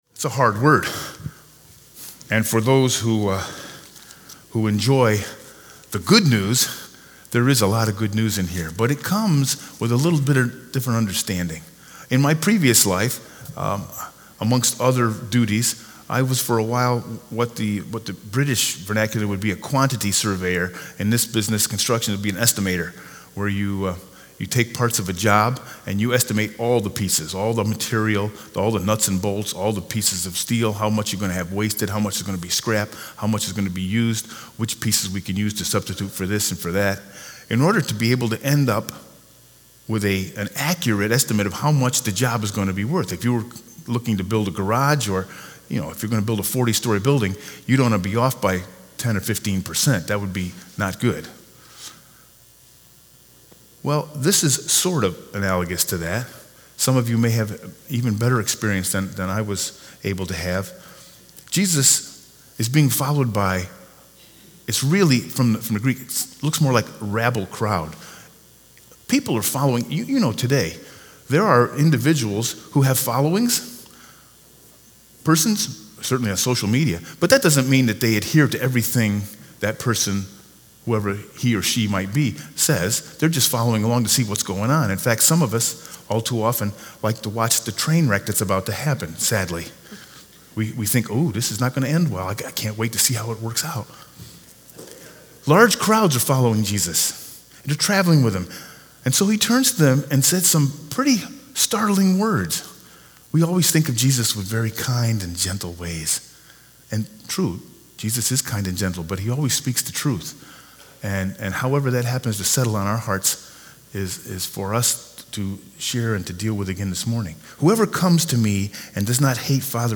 Sermon 9/8/2019